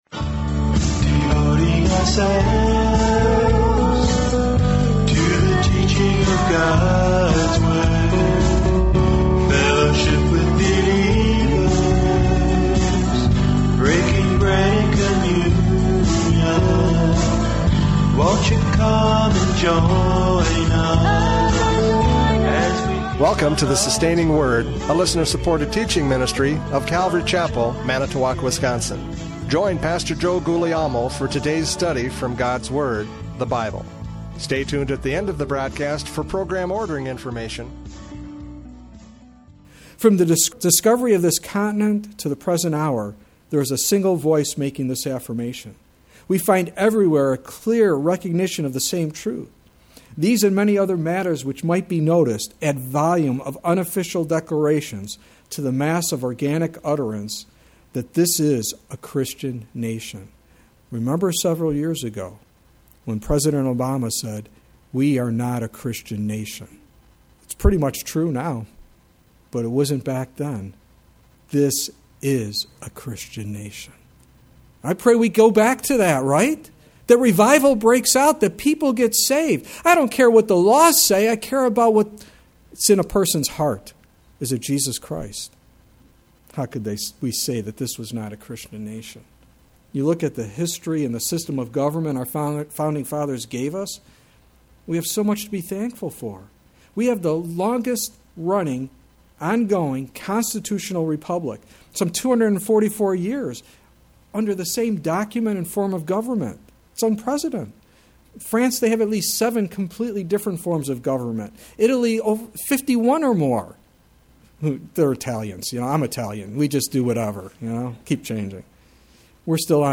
Judges 11:12-28 Service Type: Radio Programs « Judges 11:12-28 Jephthah Judges!